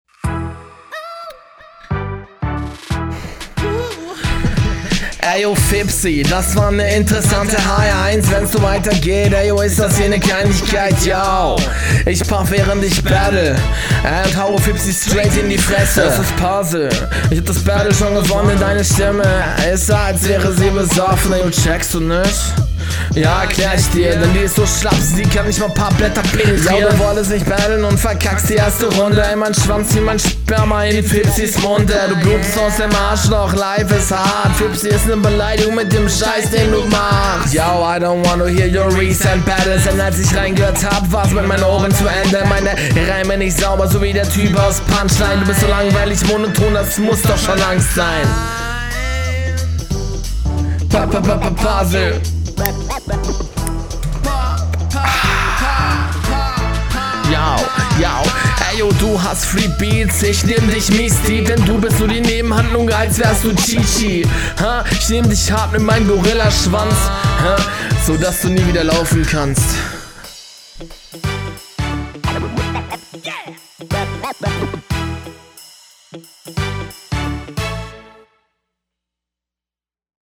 du hast eig ein echt coolen flow aber textlich wirst du hier klar von deinem …